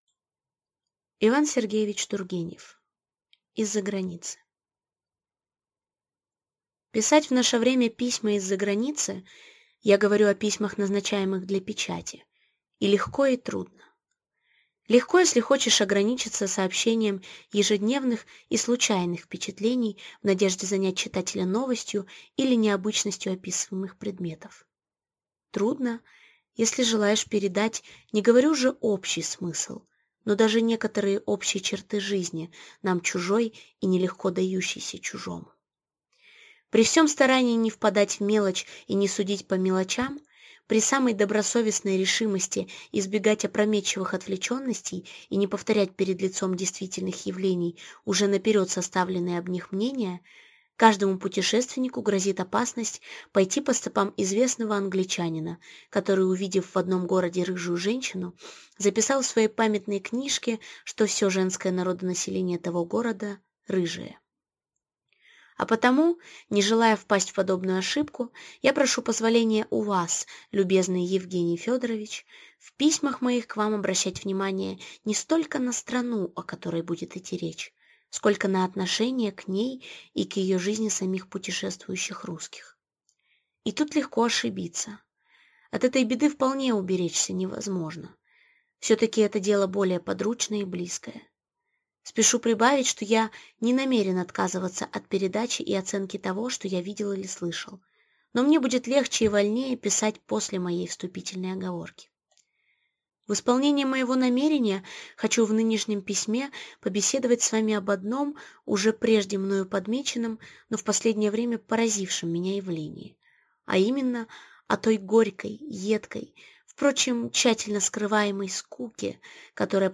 Аудиокнига Из-за границы | Библиотека аудиокниг
Aудиокнига Из-за границы Автор Иван Тургенев